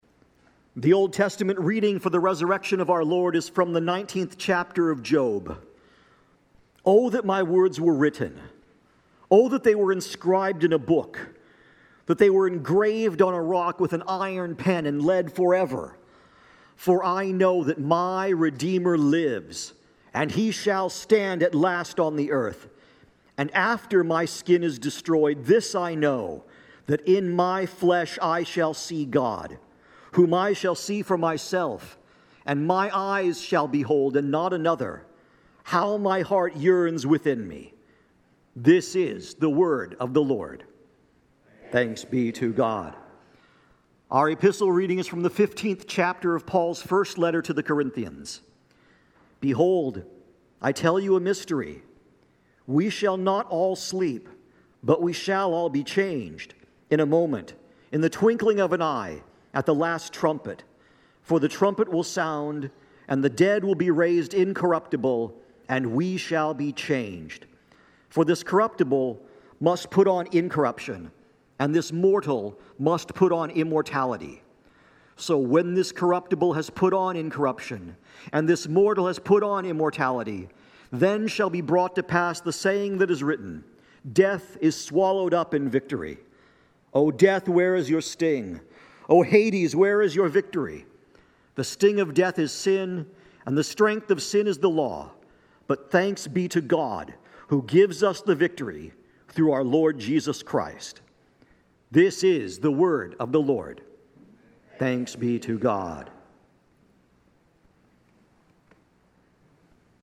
Apr 5, 2026 Easter 2nd Bible Reading – Concordia Lutheran Church Findlay